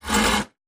in_copingsaw_stroke_04_hpx
Coping saw cuts various pieces of wood. Tools, Hand Wood, Sawing Saw, Coping